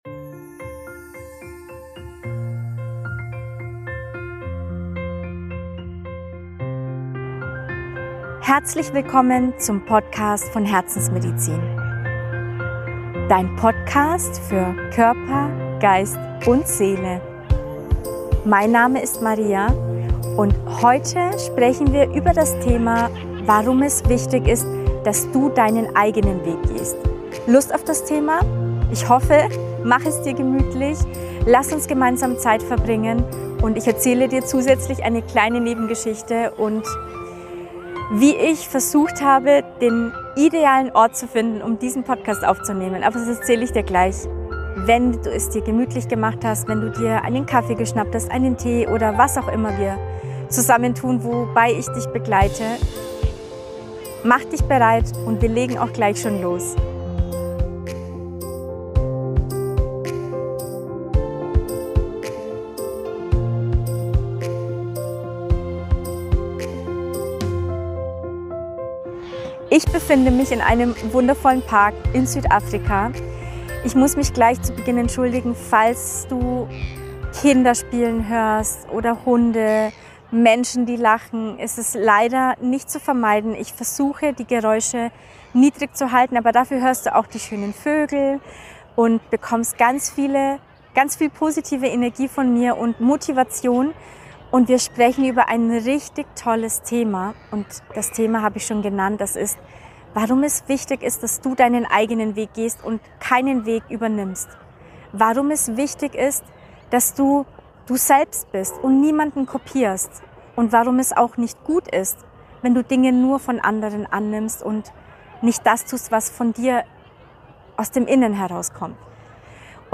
Nach mehreren Orten später habe ich letztendlich eine Platz gefunden umgeben von vielen Familien und spielenden Kindern und leider nicht ganz so ruhig, wie ich mir das gewünscht habe.